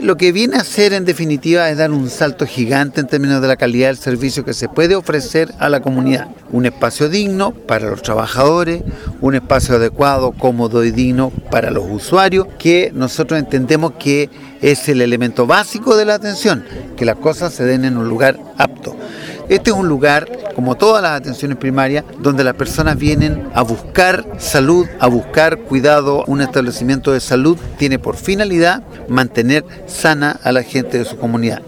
Por su parte, el director del Servicio de Salud de Osorno, Rodrigo Alarcón, subrayó que este centro viene a dar un salto gigante en términos de la calidad del servicio que se puede ofrecer a la comunidad, un espacio digno para los trabajadores, un espacio adecuado, cómodo y digno para los usuarios.